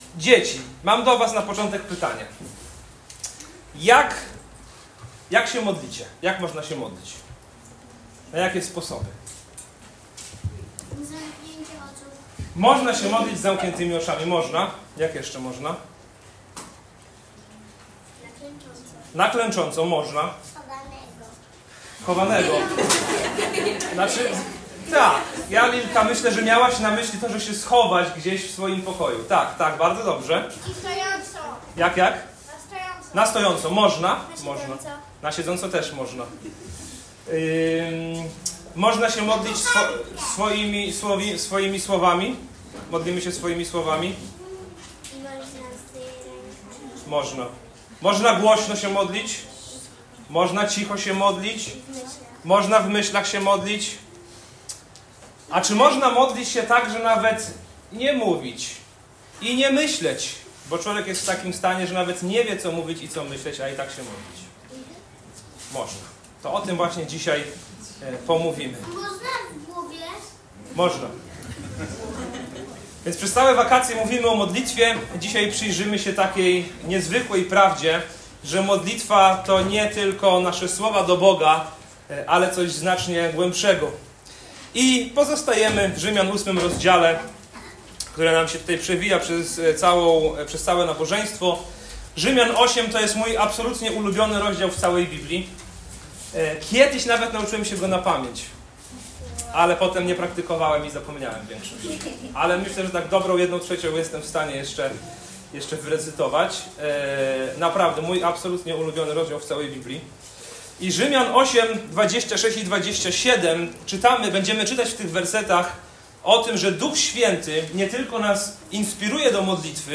W tym kazaniu odkryjesz, jak Duch Święty wspiera nas w modlitwie, nawet gdy brak nam słów i sił. Zobaczysz, jak Bóg prowadzi nas przez cierpienie, dając nadzieję i pewność, że nasza przyszłość w Chrystusie jest pełna chwały.